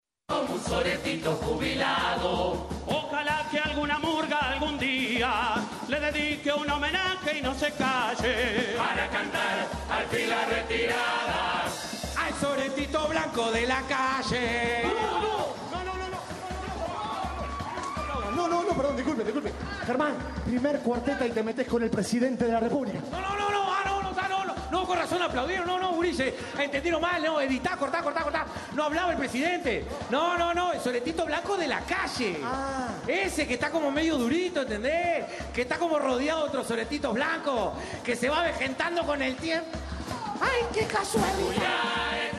«Ojalá que alguna murga algún día le dedique un homenaje y no se calle, para cantar al fin la retirada, al soretito blanco de la-calle» canta Asaltantes con Patente e ironiza en el intento de explicar que no hablaban del mandatario, sino de «la calle.»
Asaltantes con Patente fue la primera murga en presentarse tras las postergaciones de lunes y martes en el Teatro de Verano, por Covid-19 y situación climática respectivamente.